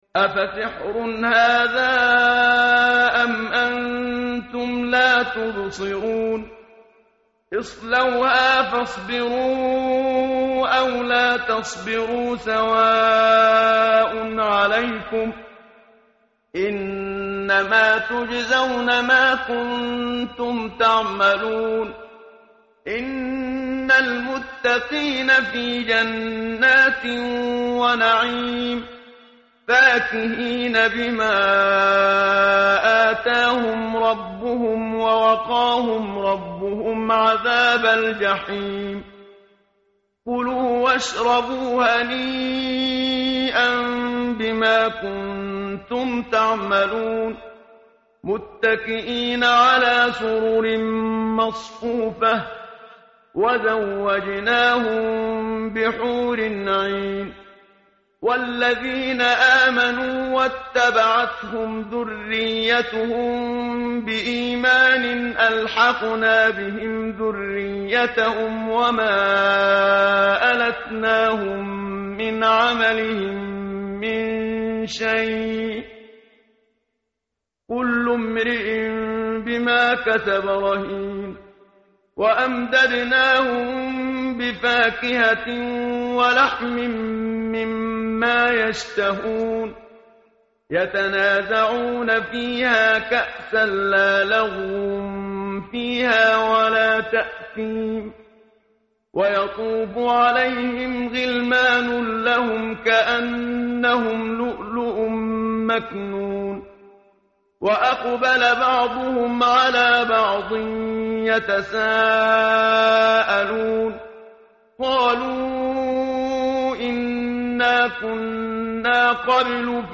tartil_menshavi_page_524.mp3